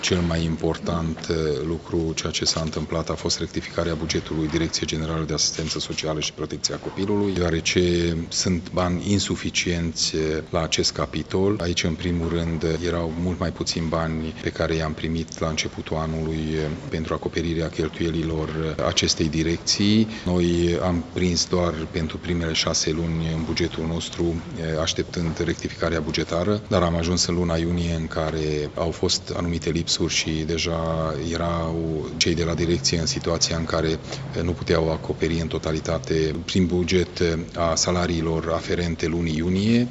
Președintele Consiliului Județean Mureș, Peter Ferenc: